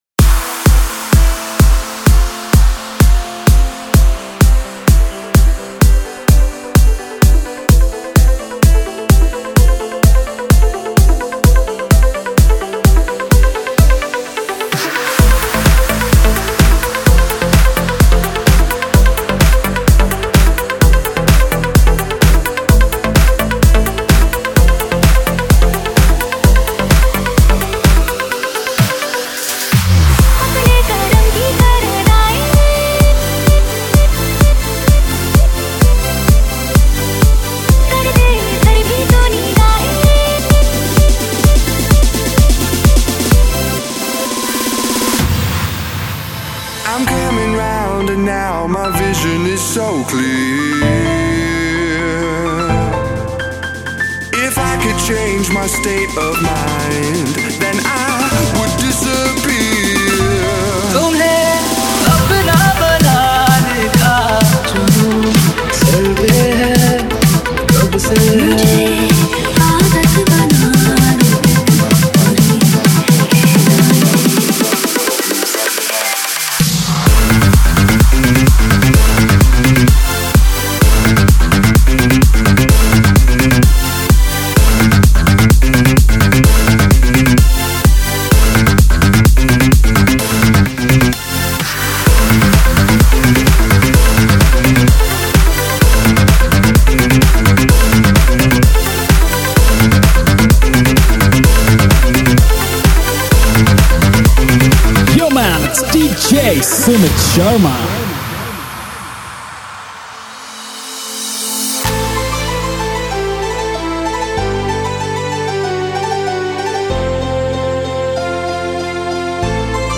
DJ Remix
Single Mixes